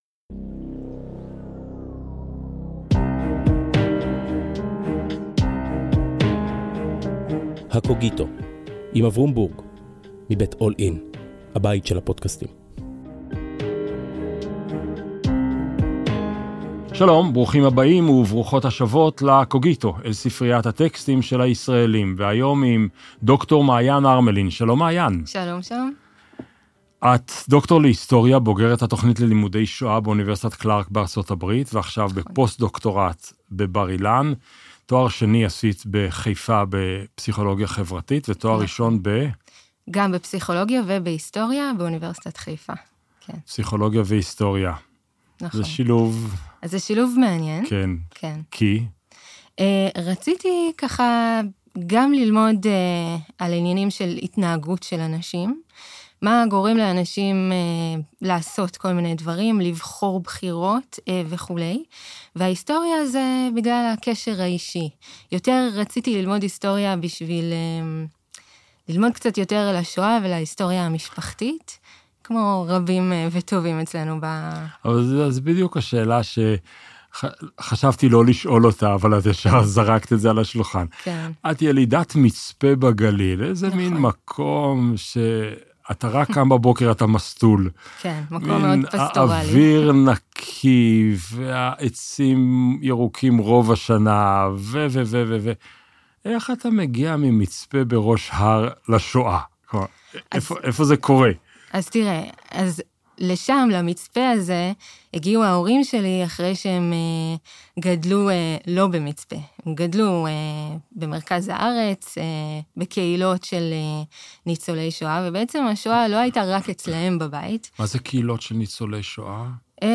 לכל איש ואישה יש טקסט, עליו נבנים המון מגדלי חיים. בפודקסט שבועי משוחח אברום בורג עם דמות מובילה אחרת בשדה התרבות והרוח על הטקסט המכונן של חייה. שיחה לא שיפוטית, קשובה אבל מאתגרת.